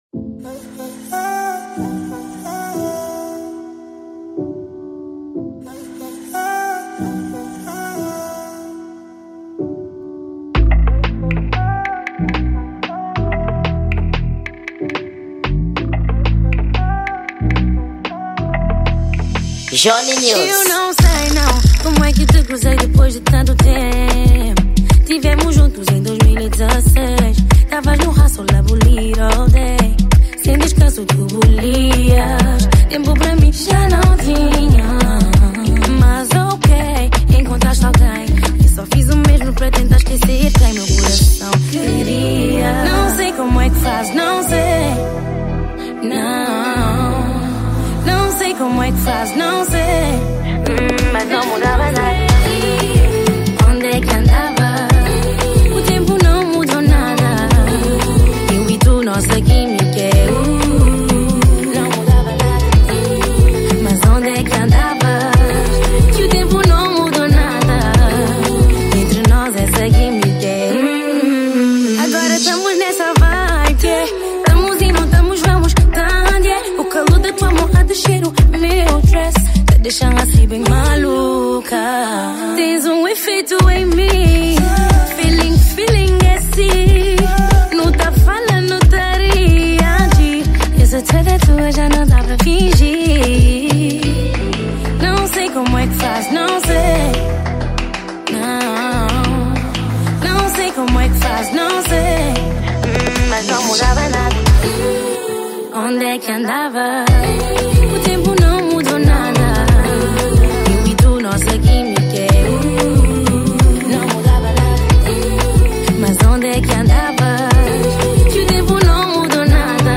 Gênero: Afro Beat